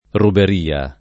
ruber&a] s. f. — varianti diffuse fin verso il ’600, spec. fuor di Tosc., perlopiù nel sign. preciso di «rapina»: rubberia [rubber&a]; rubbaria [rubbar&a]; rubaria [rubar&a]; robaria [robar&a]; robbaria [robbar&a] — cfr. rubare